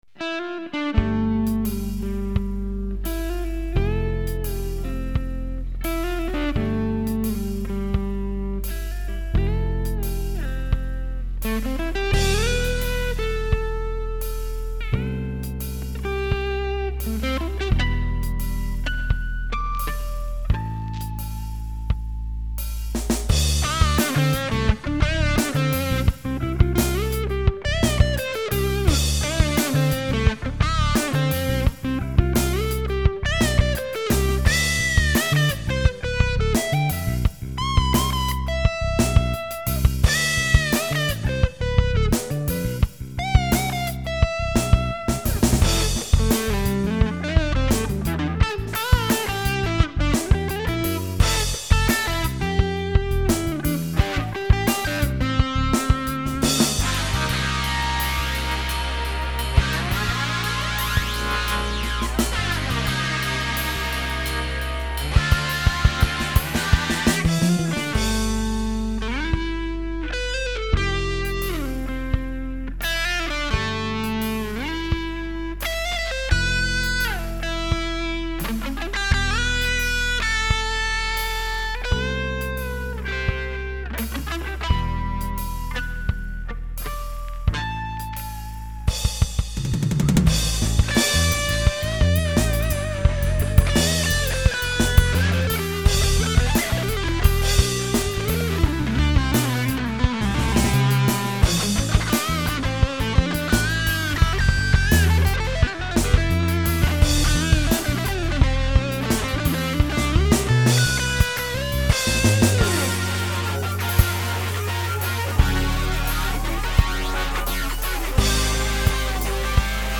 ブルース要素の高いギターインストのバラードです。